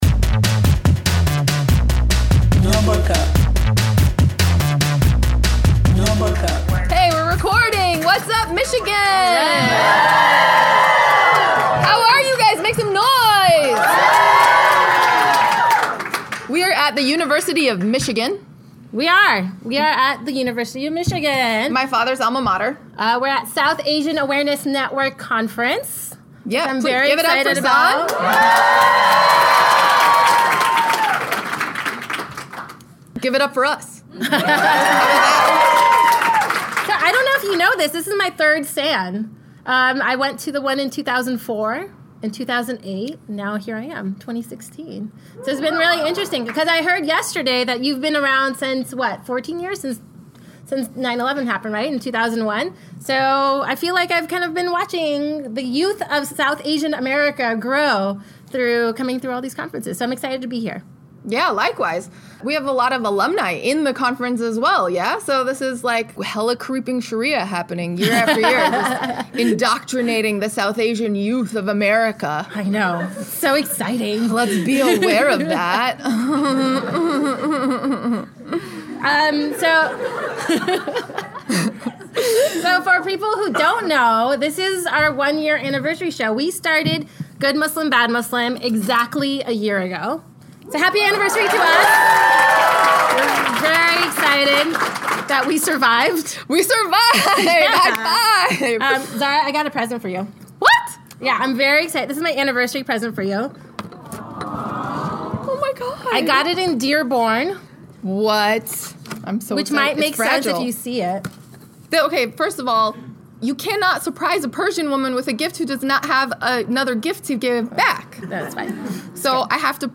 013 - LIVE from University of Michigan